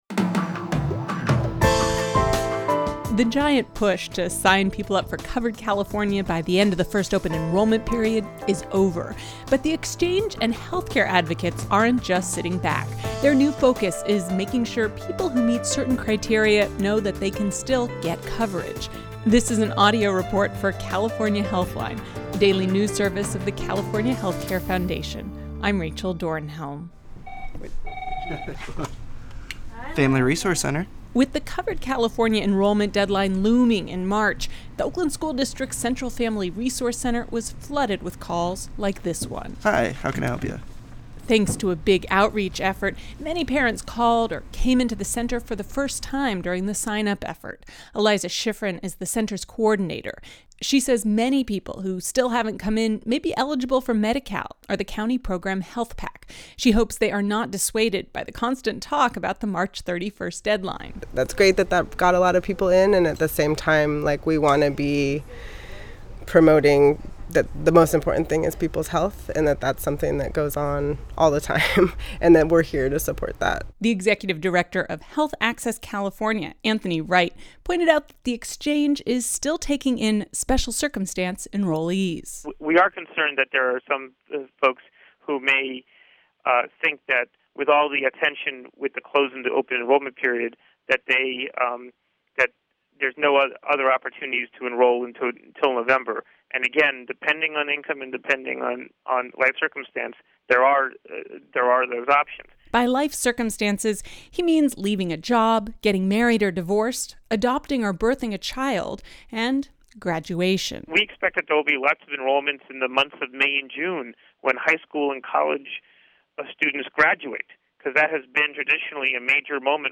The report includes comments from:
Audio Report Insight